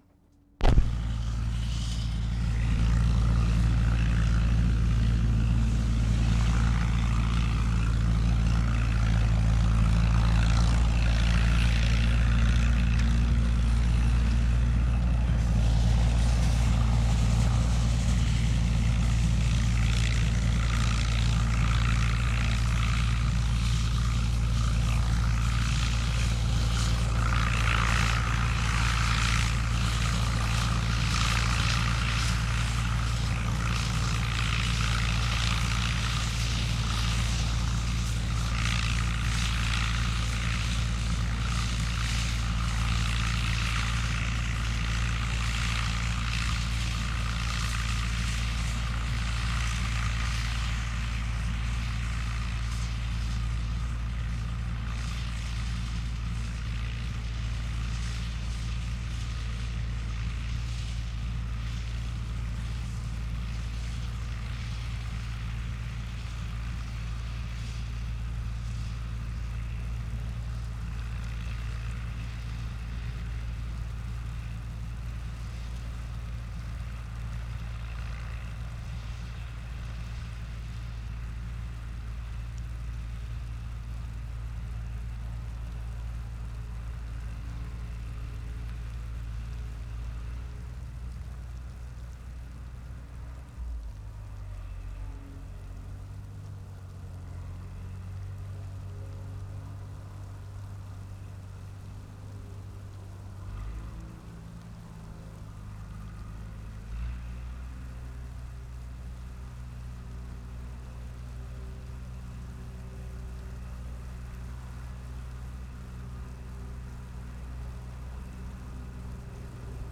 AYR, ONTARIO Nov. 5, 1973
5. Tractor and cornpicking attachment, operating in a field. This was recorded at dusk, a light snow falling (first snow of the season). The tractor sound shifts in the wind when it is in the distance. Tractor is at furthest point in the field at 4'00" and returns, gets closer by 5'00".